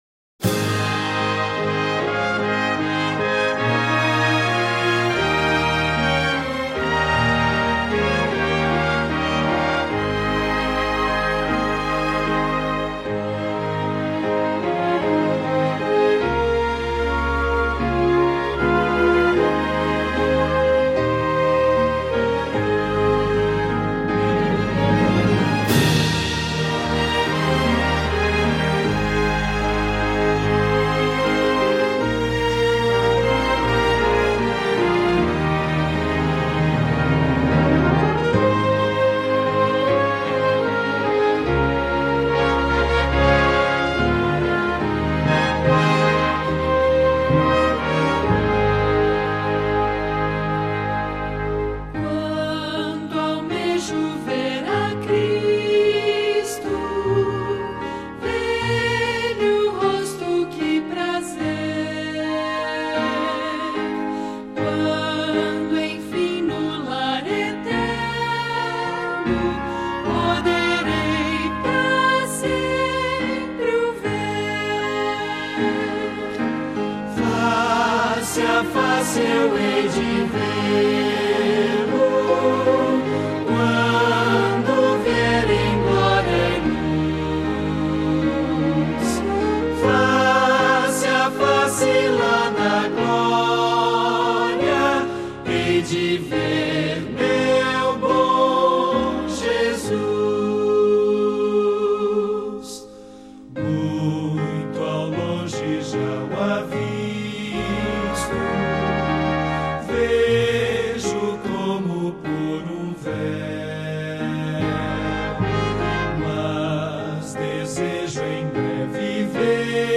Ao ouvir o hino a seguir, ao ler sua mensagem, me lembro disto.
Mesmo não sendo cantor nem músico, há alguns anos dava-me, a mim mesmo, o privilégio de cantá-la e tocá-la, ao violão, para um auditório de uma única pessoa (quando muito, mais uma ou duas).